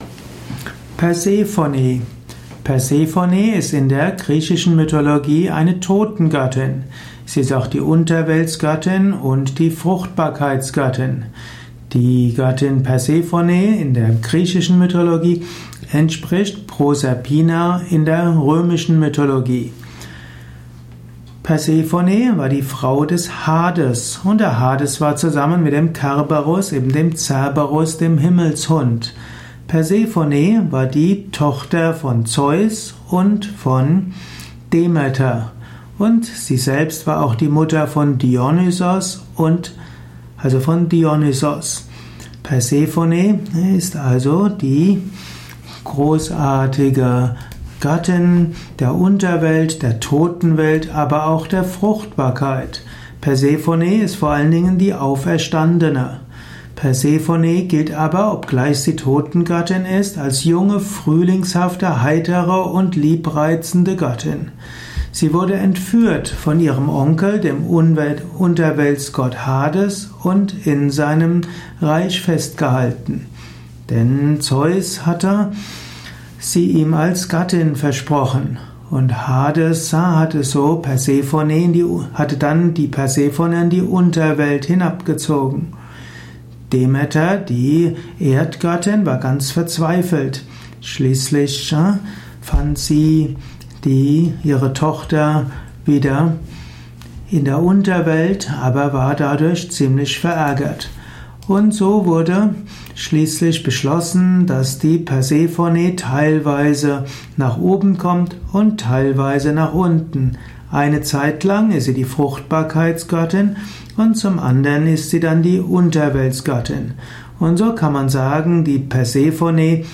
Ein Audio Vortrag über Persephone, einem griechischen Gott. Beschreibung der Signifikanz von Persephone in der griechischen Mythologie, im griechischen Götterhimmel.
Dies ist die Tonspur eines Videos, zu finden im Yoga Wiki.